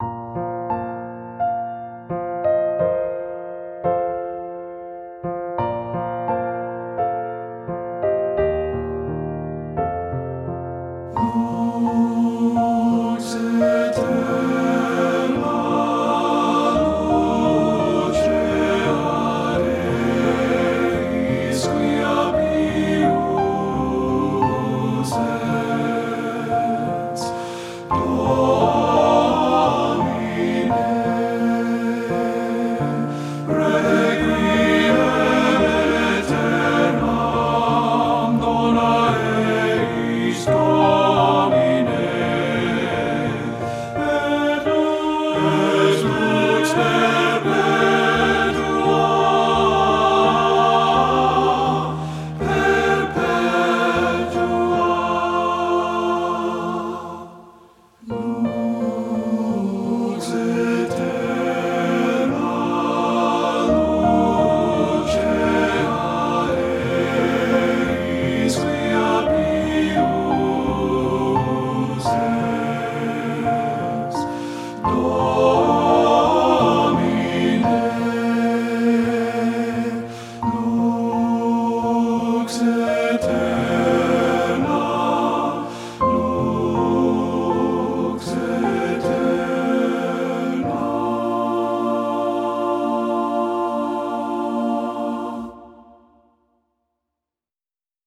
TTB Voices, a cappella with Optional Piano
• Piano
Studio Recording
Ensemble: Tenor-Bass Chorus
Key: B major
Tempo: q = 86
Accompanied: Accompanied Chorus